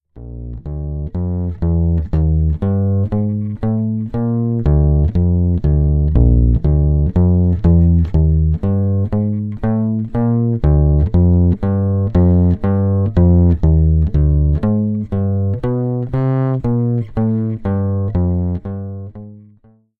で、その音をPCMレコーダーでライン録りしてみました[
各弦の音量のバランスが良くなり、ノイズも少ないので満足です。
underwood_optical.mp3